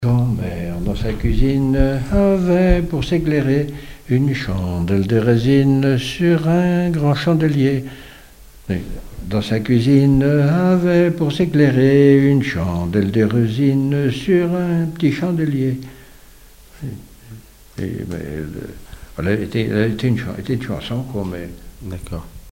Pouzauges
Témoignages et chansons
Pièce musicale inédite